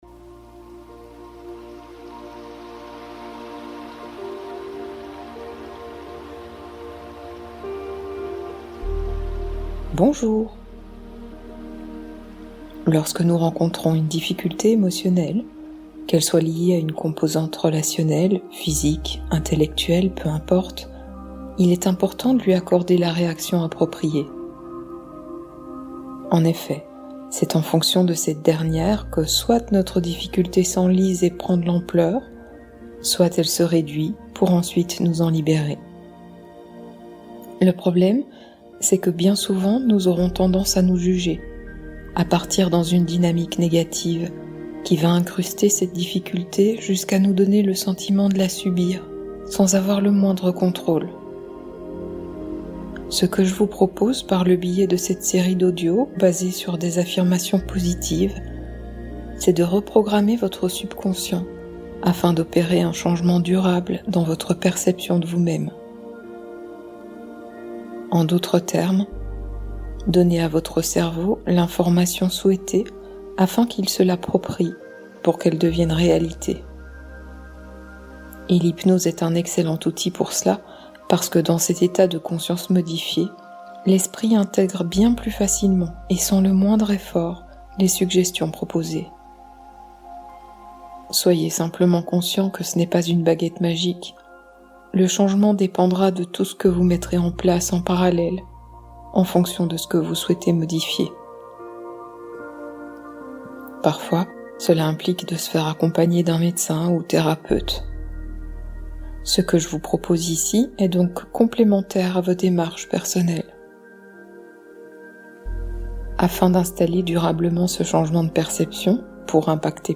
Devenez non-fumeur pendant votre sommeil (hypnose de reprogrammation totale)